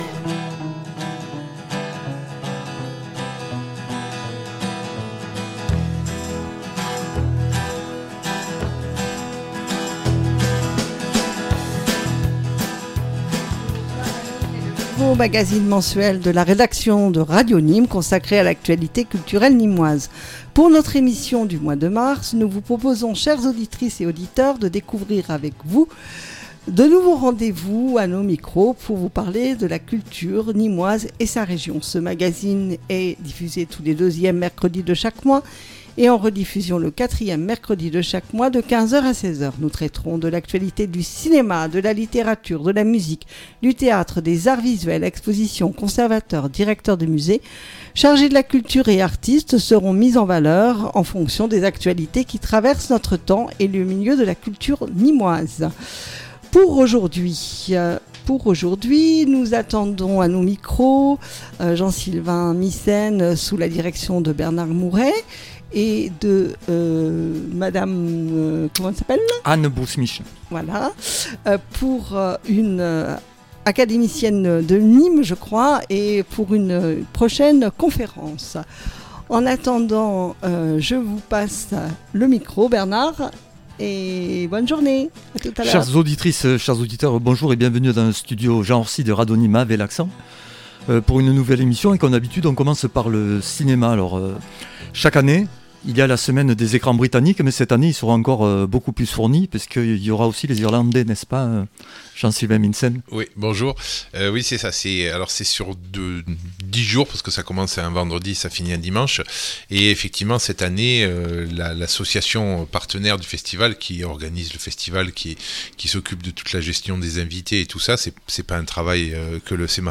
A retrouver sur Radio Nîmes en direct tous deuxièmes et quatrièmes Mercredi du mois à 15h00 !